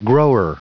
Prononciation du mot grower en anglais (fichier audio)
Prononciation du mot : grower